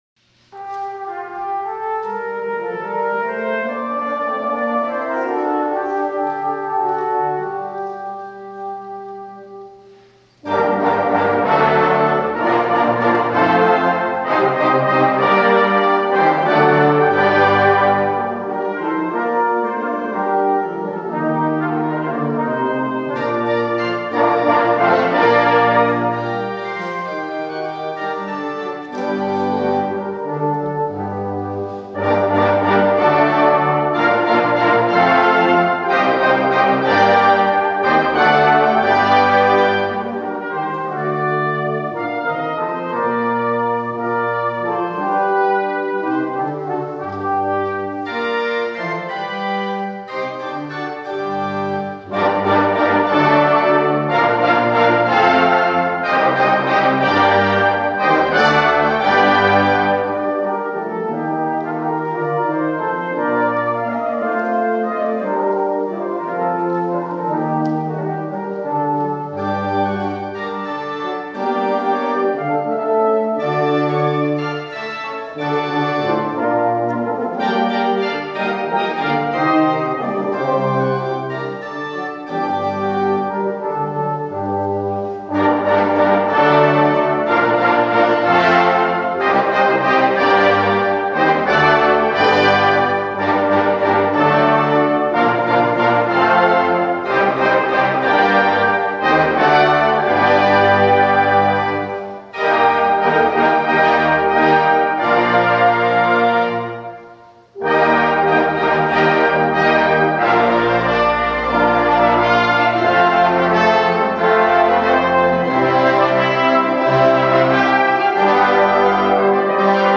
A Few Performance Recordings | Chester Brass Band
organ